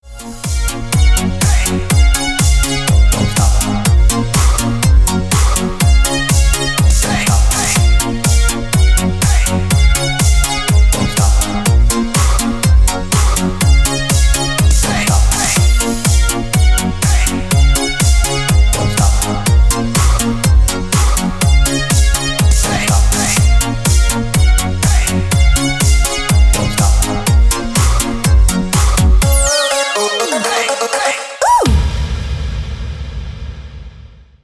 Диско мелодия на мобилу скачать на телефон онлайн.